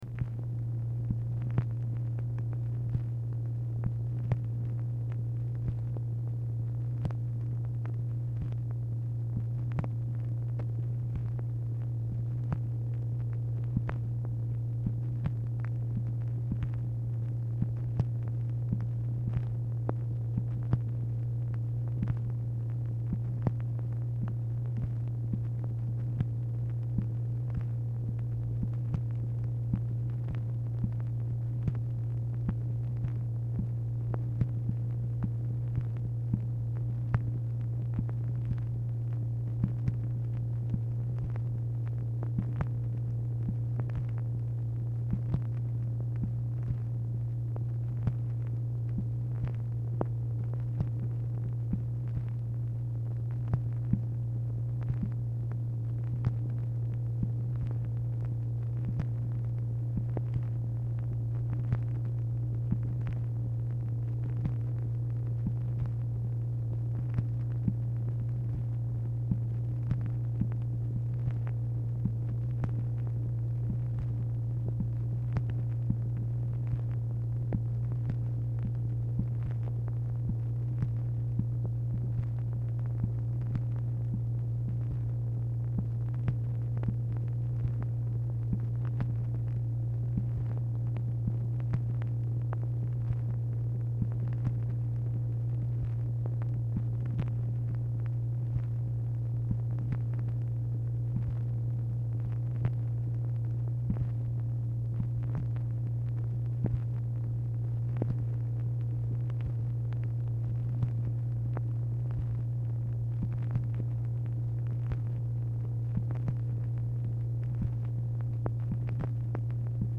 Format Dictation belt
Location Of Speaker 1 LBJ Ranch, near Stonewall, Texas
Speaker 2 MACHINE NOISE